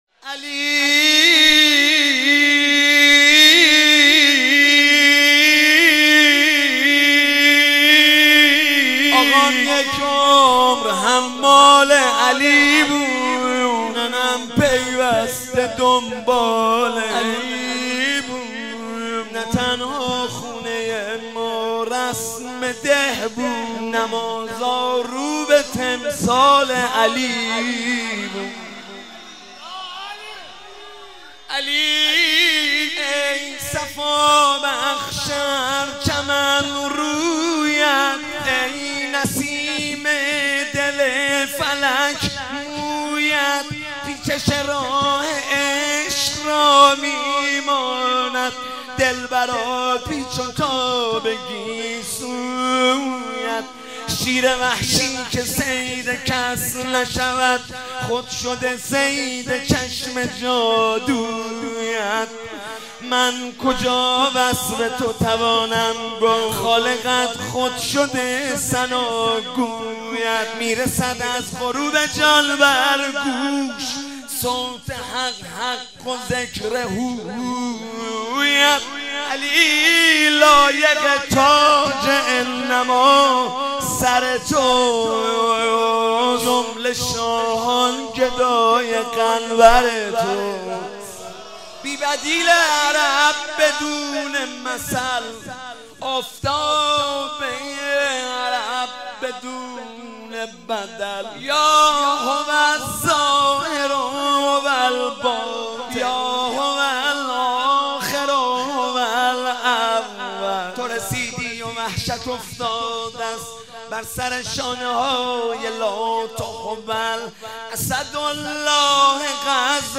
شهادت حضرت زینب (س) 98 | هیئت عاشقان حضرت زینب (س) خمینی شهر